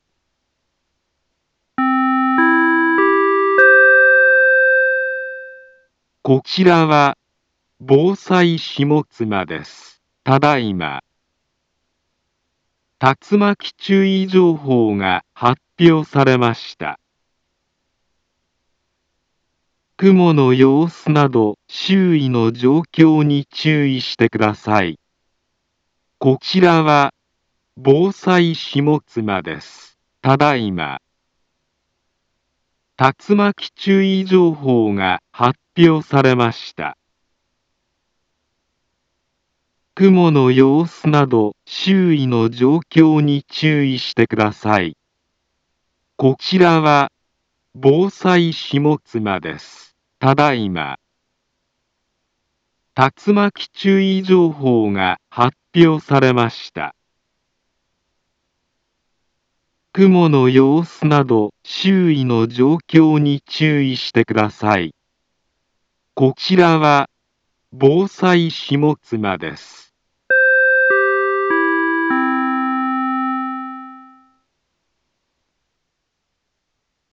Back Home Ｊアラート情報 音声放送 再生 災害情報 カテゴリ：J-ALERT 登録日時：2022-08-03 16:09:43 インフォメーション：茨城県北部、南部は、竜巻などの激しい突風が発生しやすい気象状況になっています。